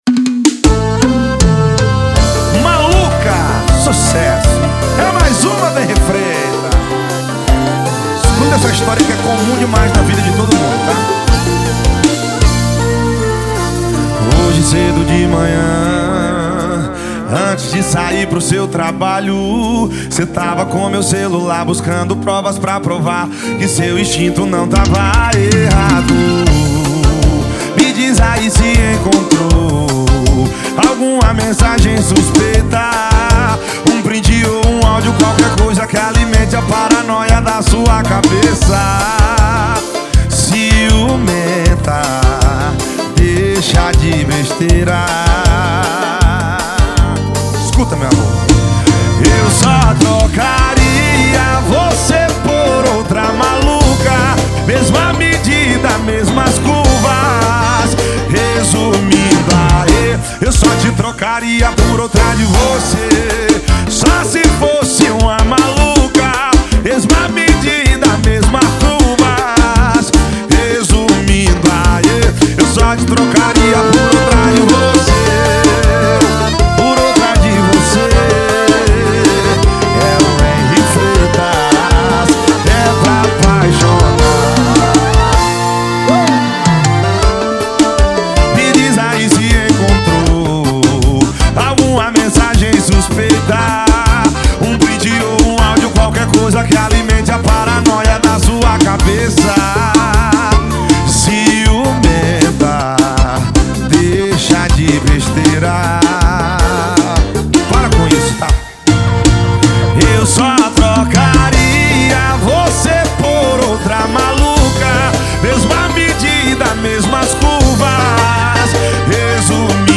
2024-02-14 18:37:06 Gênero: Forró Views